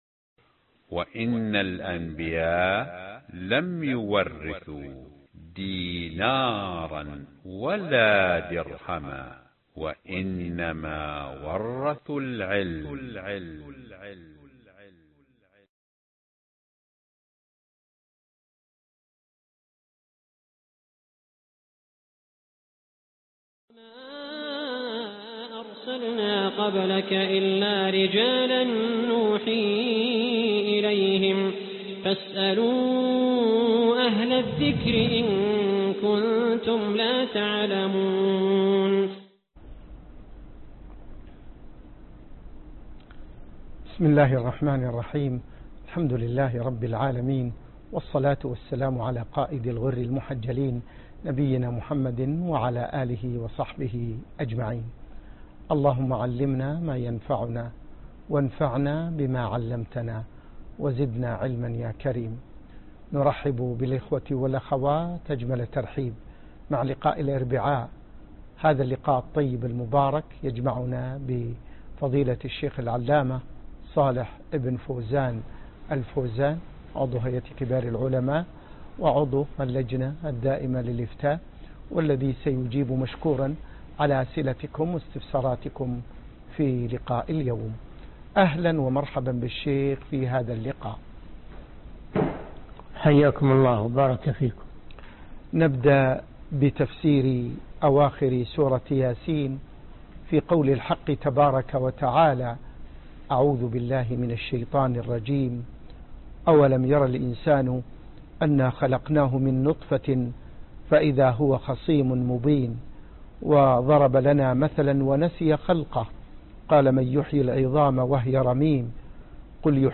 فتاوي مع تفسير أواخر سورة يس (14/3/1435)هـ (فتاوي علي الهواء) - الشيخ صالح بن فوزان الفوازان